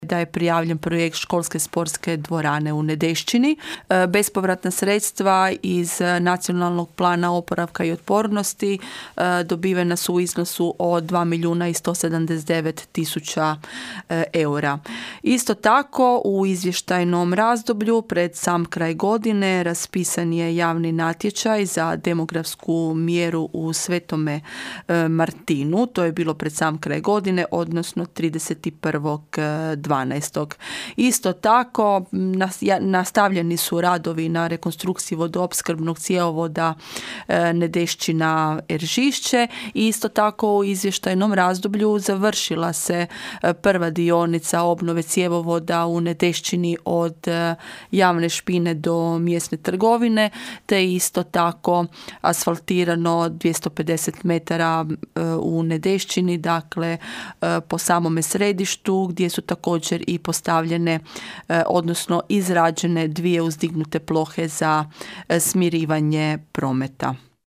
Načelnica Svete Nedelje Irene Franković na sjednici Općinskog vijeća predstavila je izvještaj o radu za drugu polovicu prošle godine.